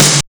GRUNGE SNR.wav